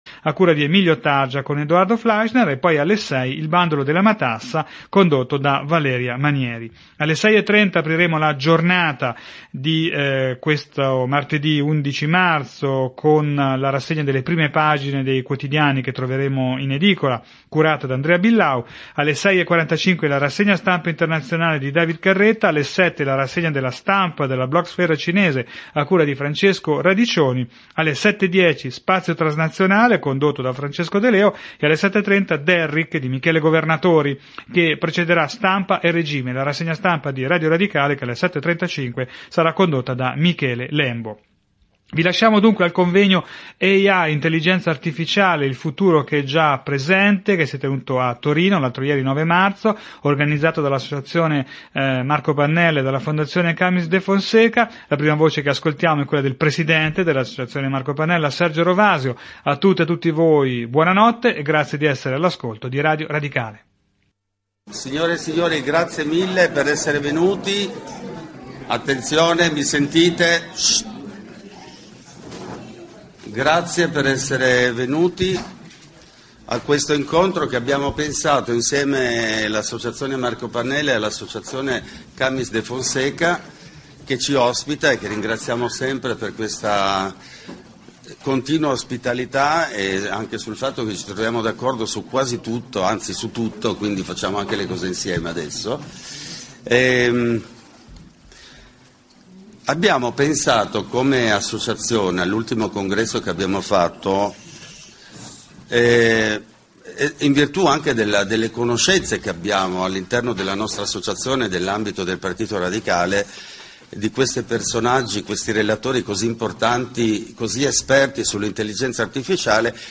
convegno_ai_2025.mp3